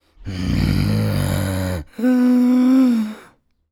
Zombie Voices Demo
zombie_breath_5.wav